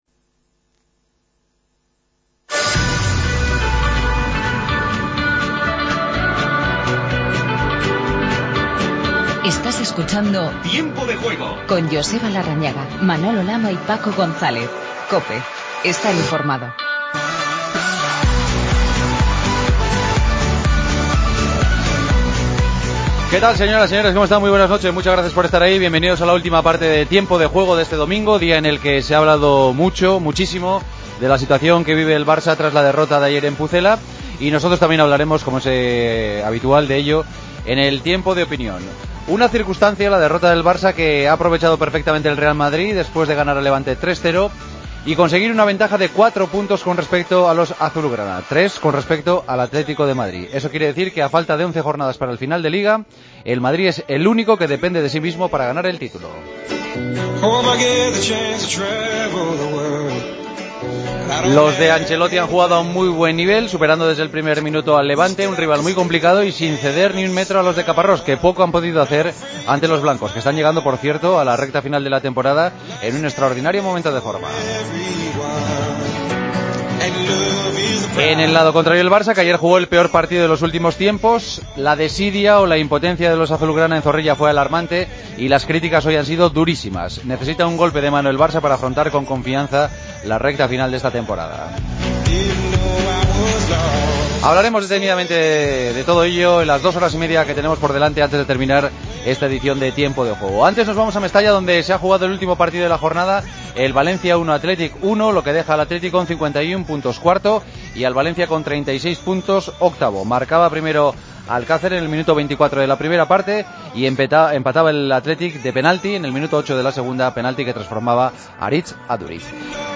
Entrevistamos a los protagonistas del Madrid - Levante y Valencia - Athletic.
Escuchamos en zona mixta a Ramos y a Di María.